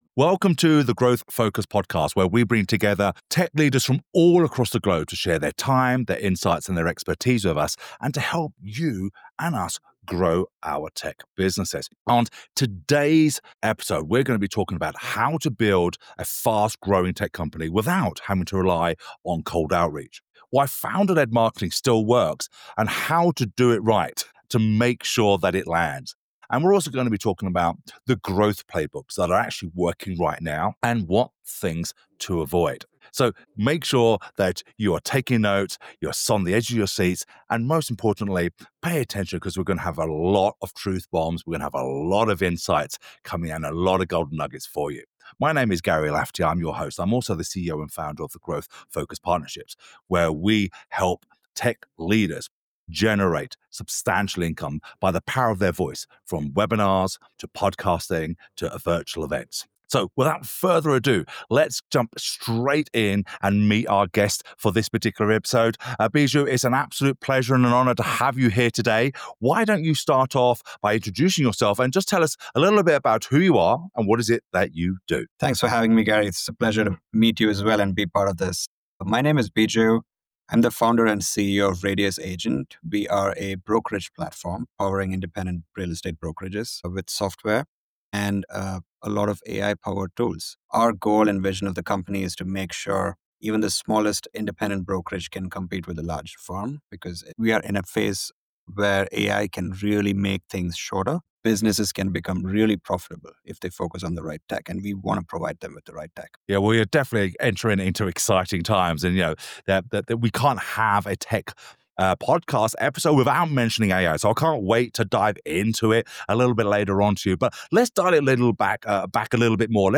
This is a candid, insight-rich conversation for tech founders, agency leaders, and startup execs looking to scale fast, without losing their soul.